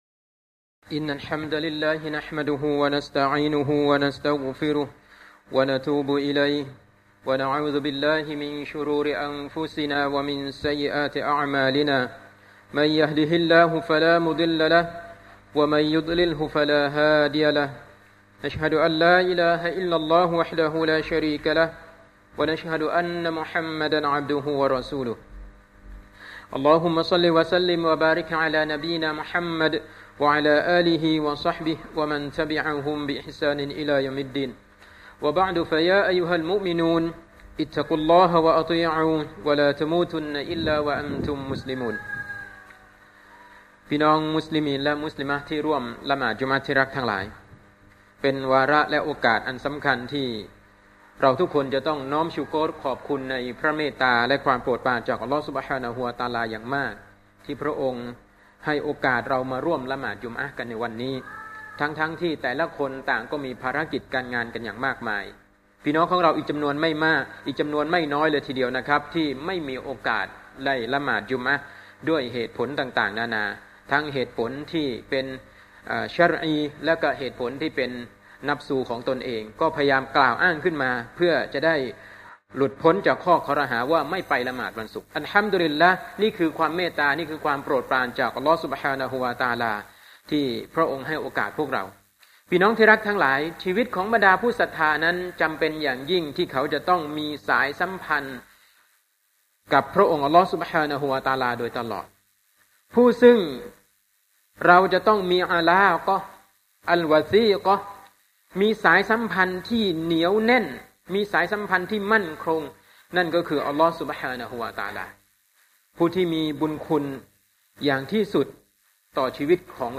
คุตบะห์วันศุกร์ที่ 5 มิถุนายน 2552